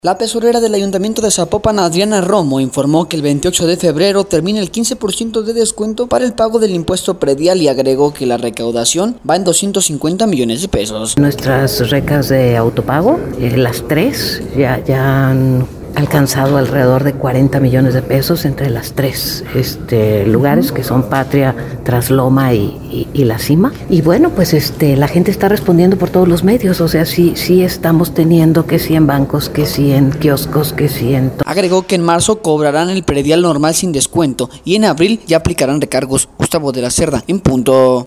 La tesorera del ayuntamiento de Zapopan, Adriana Romo, informó que el